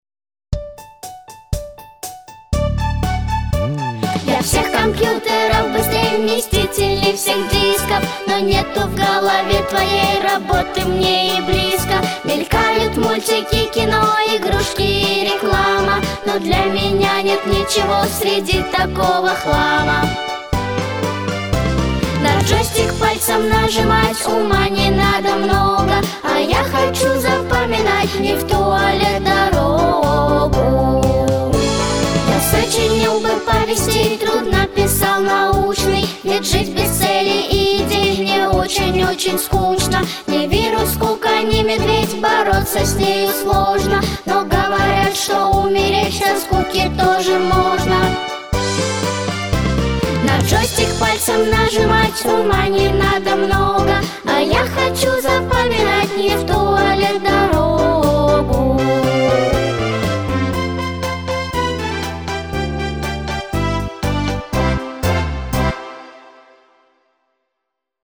III Театральный Фестиваль начальной школы